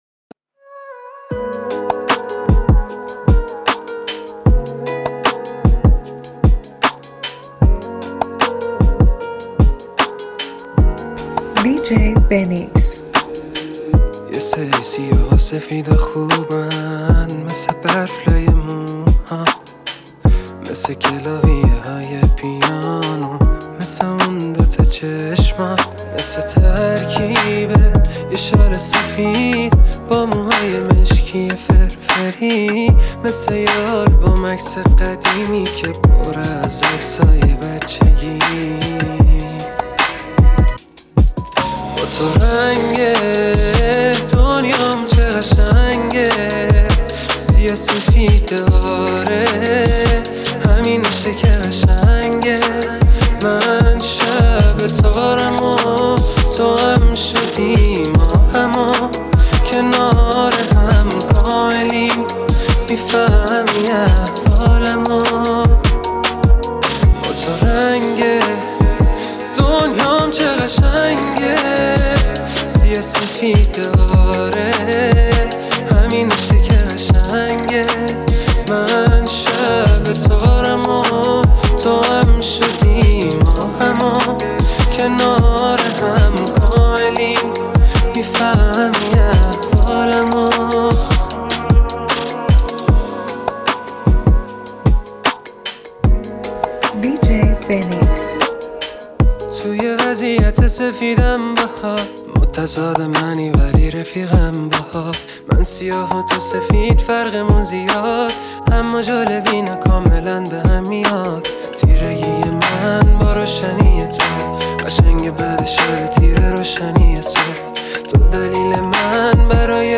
تند بیس دار Remix